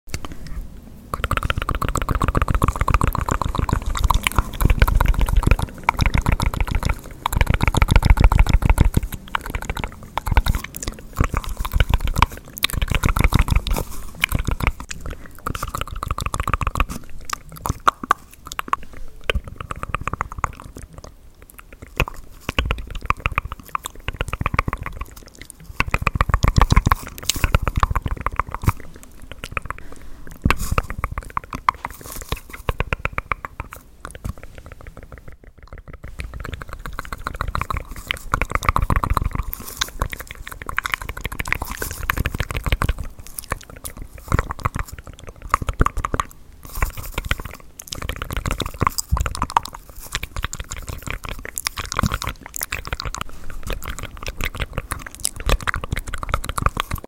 🫦Intense Mouths Sounds ASMR Sound Effects Free Download